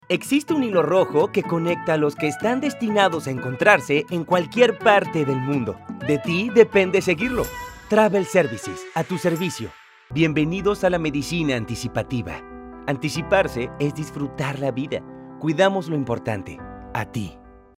Distintas tonalidades y estilos de voz
Español Neutro
Enérgica / Institucional
energica-institucional.mp3